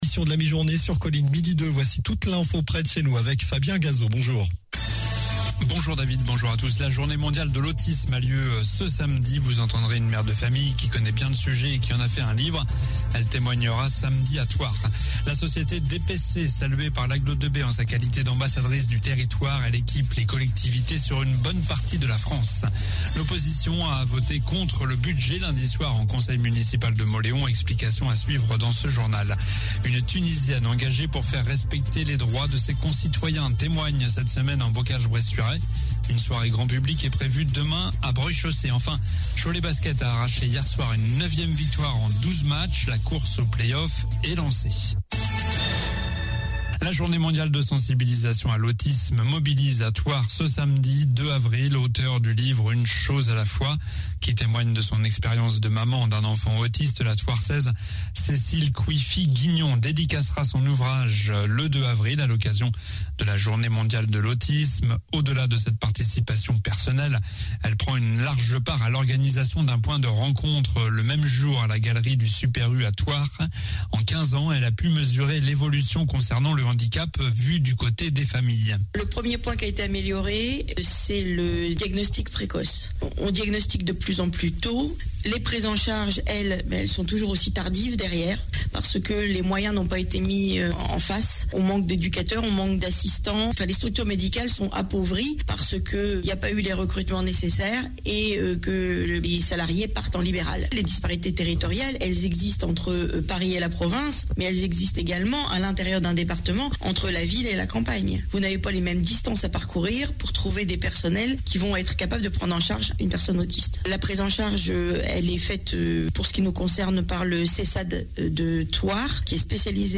Journal du mercredi 30 mars (midi)
La journée mondiale de l'autisme a lieu ce samedi, vous entendrez une mère de famille qui connait bien le sujet.